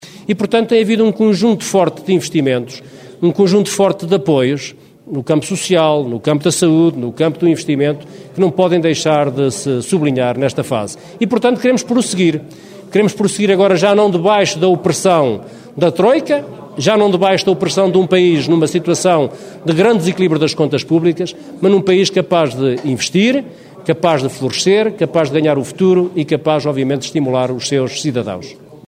Declarações de Adão Silva, na apresentação oficial da lista pela coligação PSD/CDS-PP pelo distrito de Bragança, que aconteceu em Alfândega na Fé.